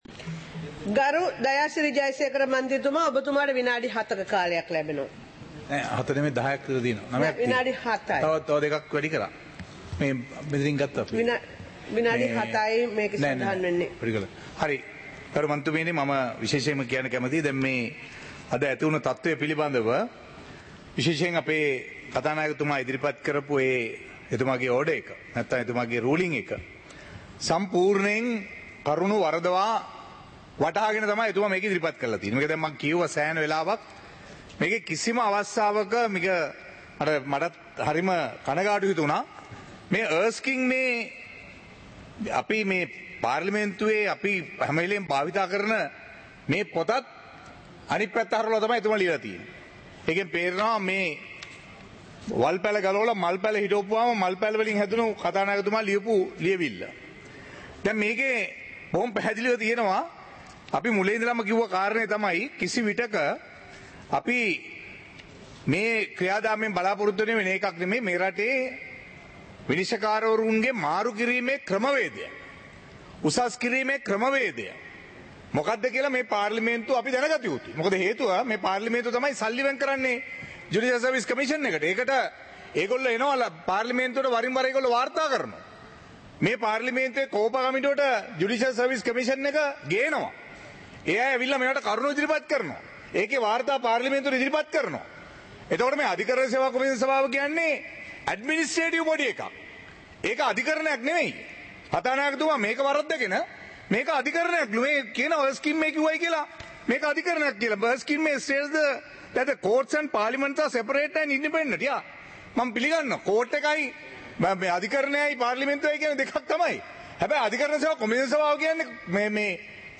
சபை நடவடிக்கைமுறை (2026-01-09)
நேரலை - பதிவுருத்தப்பட்ட